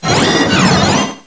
pokeemerald / sound / direct_sound_samples / cries / uncomp_regieleki.aif
uncomp_regieleki.aif